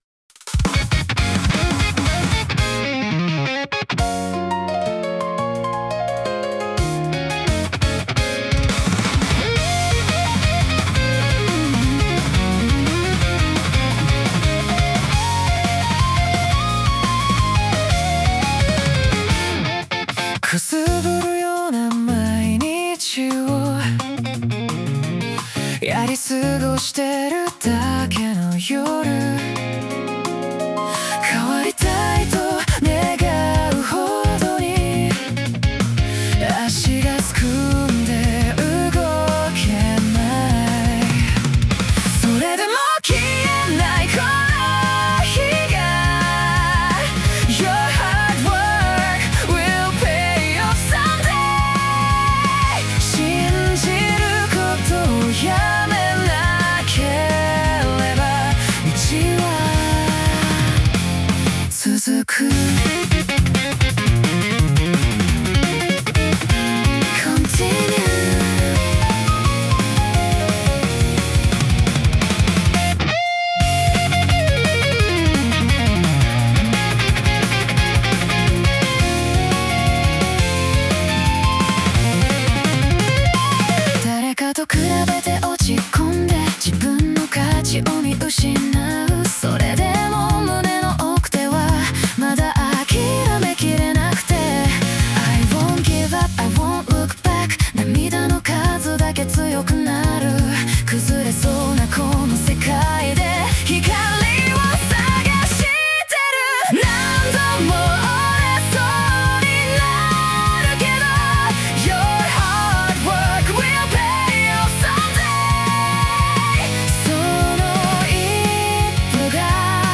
女性ボーカル
イメージ：邦ロック,J-ROCK,男性ボーカル,女性ボーカル,シューゲイザー